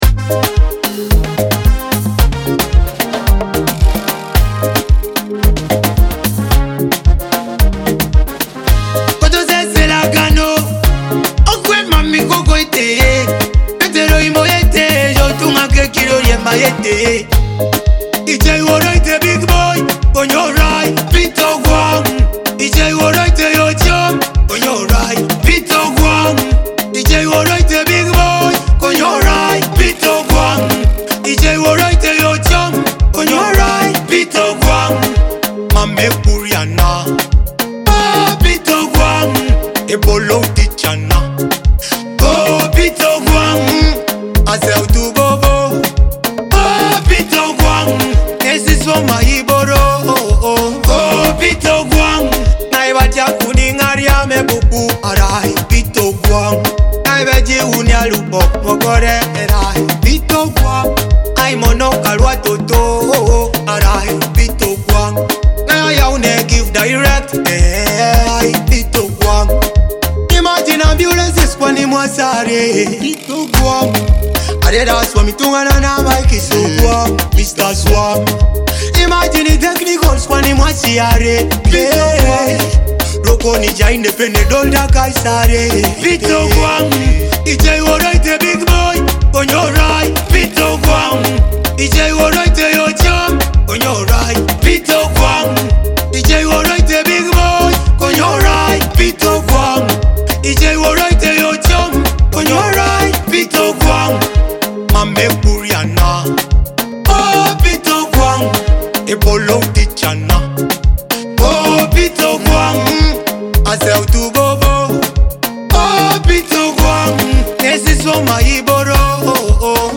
a vibrant Teso music track